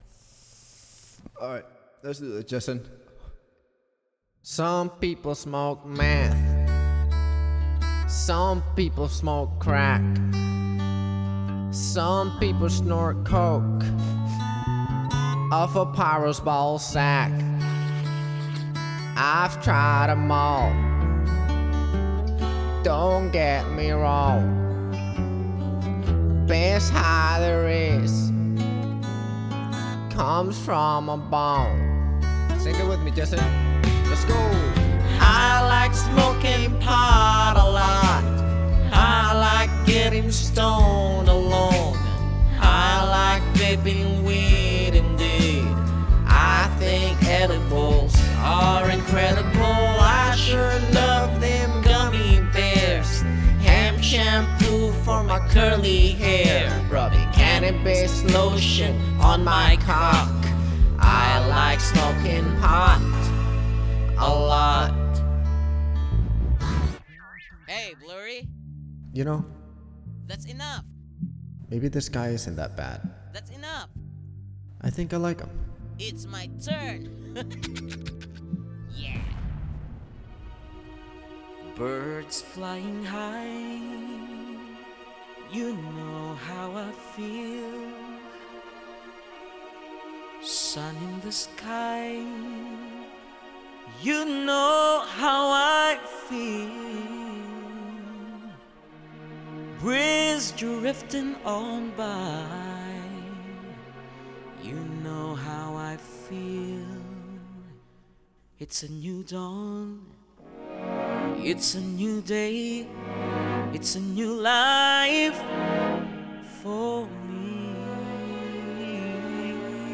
Special Performance Songs For Streaming Or Downloading